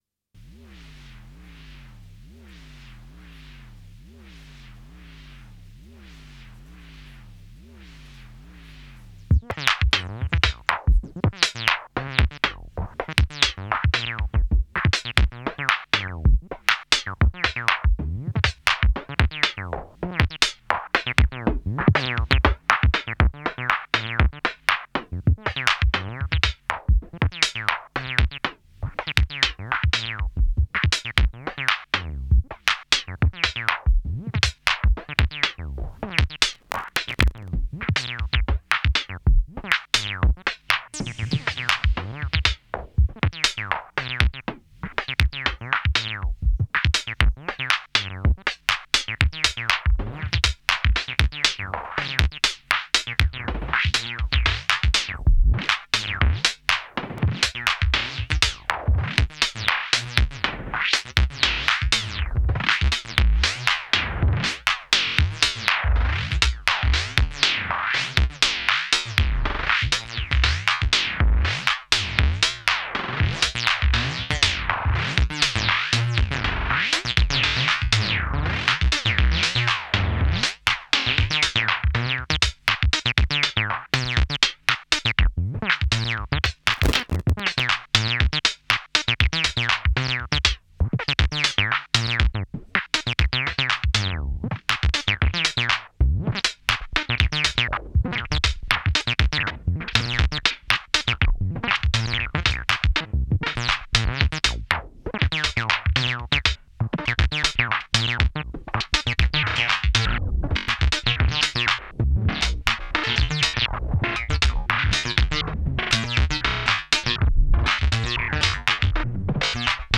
Not a full song just exploring.